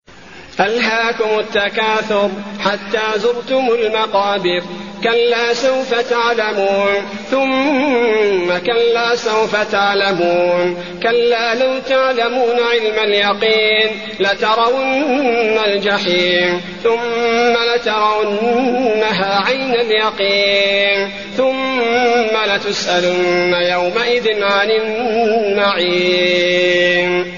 المكان: المسجد النبوي التكاثر